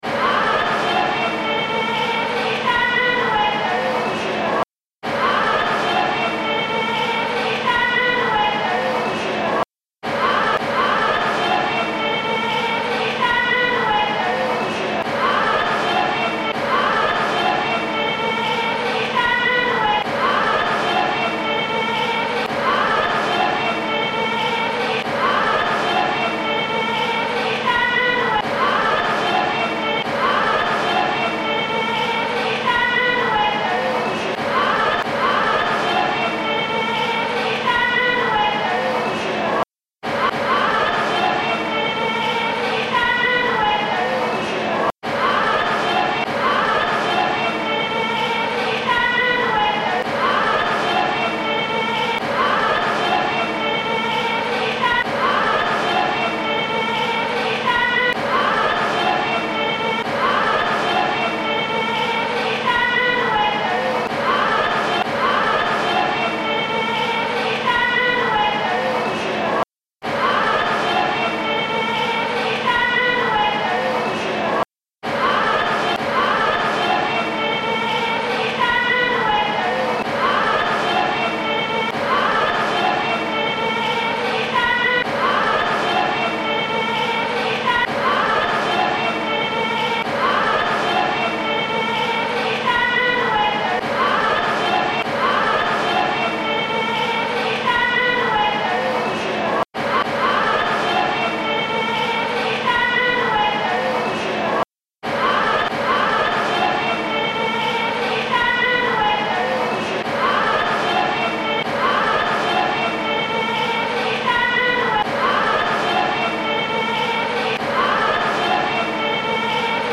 Genre laisse
Chansons traditionnelles